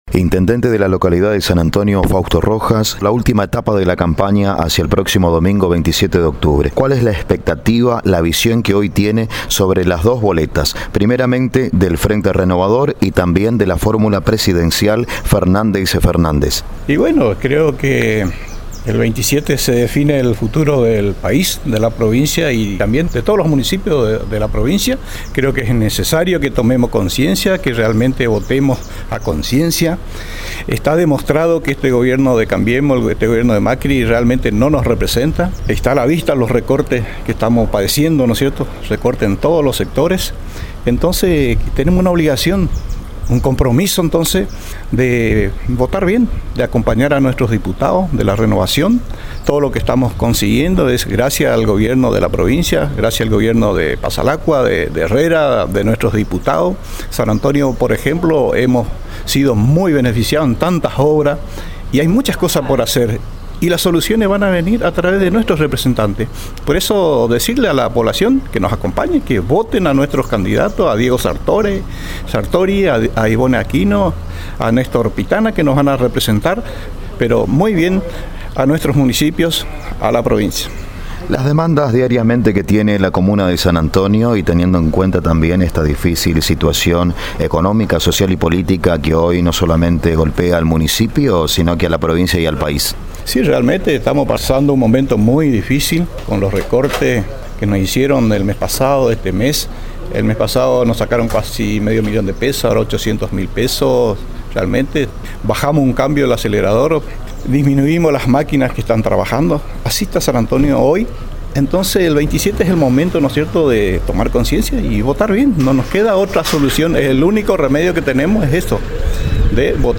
En dialogo con ANG, tanto Guillermo Fernandez intendente de Bernardo de Irigoyen, como Fausto Rojas jefe comunal de San Antonio, expresaron lo complicado que resulta cumplir con las obligaciones municipales, administrando los recursos cada vez más escasos por la crisis y la caída en la coparticipación federal, por las medidas inconsultas tomadas por el gobierno nacional, luego de perder las elecciones PASO de agosto. Coincidieron en que la situación no es peor, gracias al orden de las finanzas y el acompañamiento del gobierno provincial.